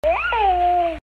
Baby Mowgli’s Squeal